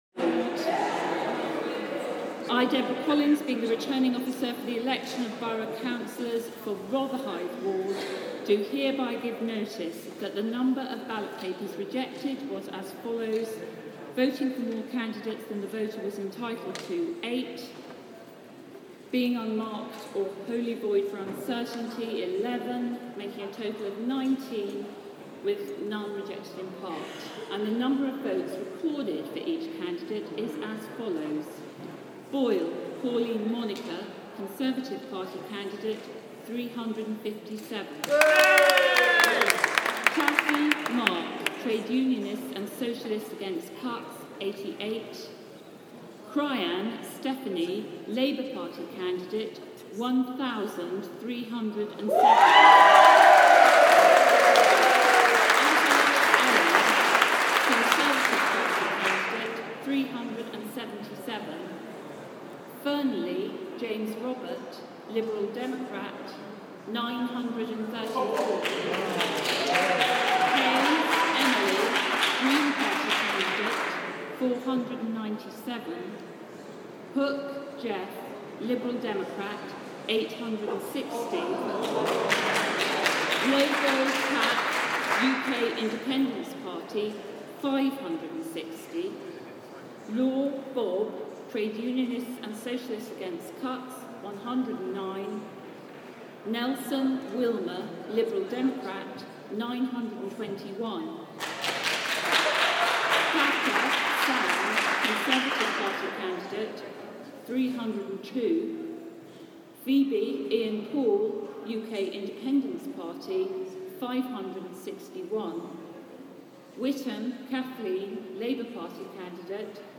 Rotherhithe ward declaration - Labour gain